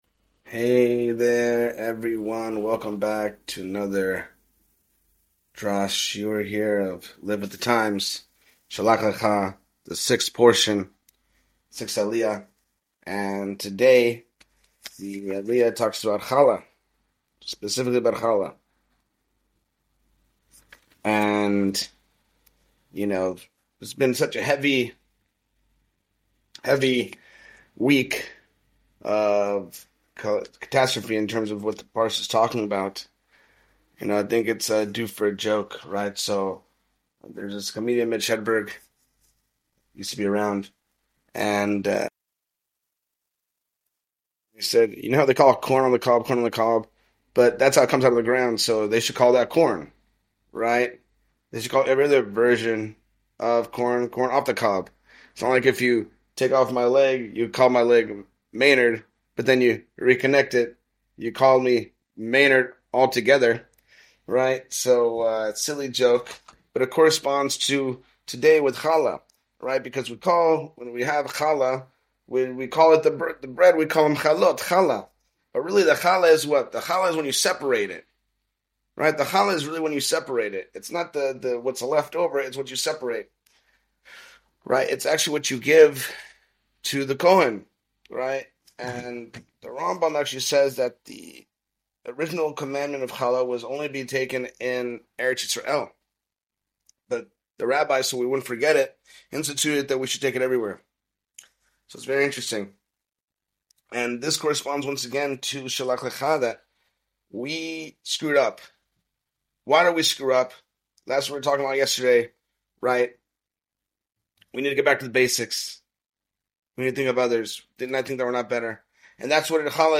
It emphasizes the importance of thinking about others and giving to others. The speaker shares a joke about corn on the cob to illustrate the idea that Challah is not just the bread, but the portion that is separated and given to the Kohen. The conversation also touches on the importance of separating oneself from the world and the concept of Kedusha (holiness).